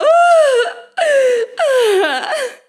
Grito de una folclórica
grito
Sonidos: Acciones humanas
Sonidos: Voz humana